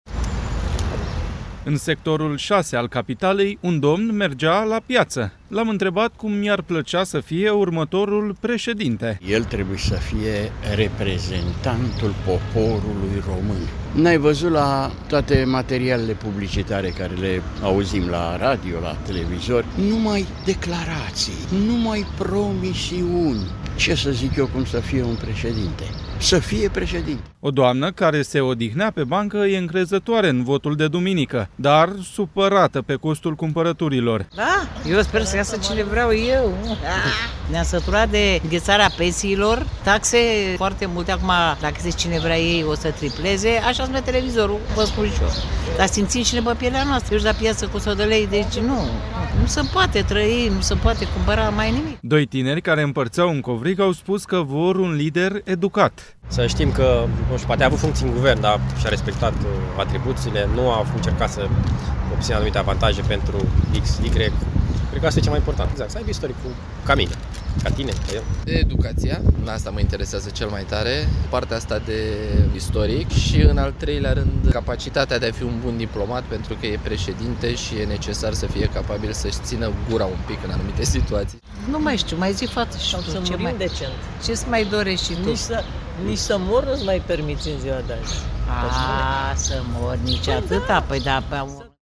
În Sectorul 6 al Capitalei, un domn mergea la piață.
O doamnă care se odihnea pe bancă e încrezătoare în votul de duminică, dar supărată pe costul cumpărăturilor.